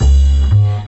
G_07_Bass_01_SP.wav